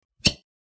Suction-cup-80187.mp3